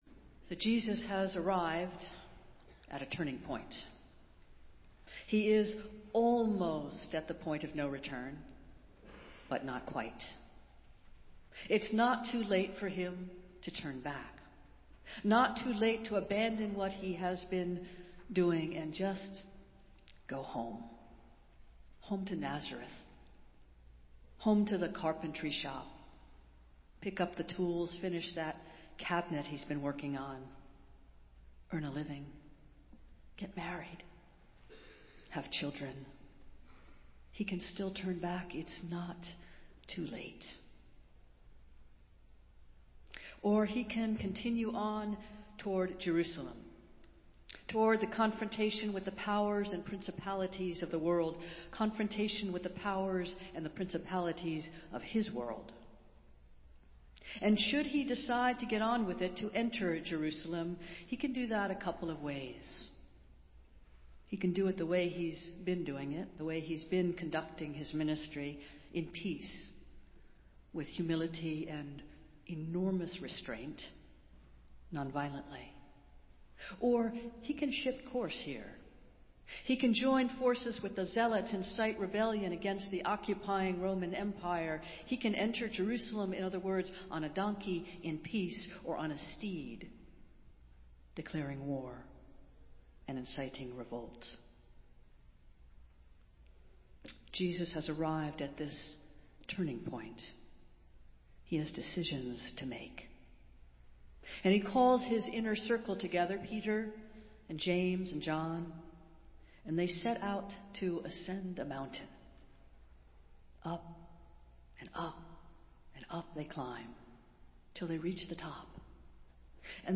Festival Worship - Annual Meeting Sunday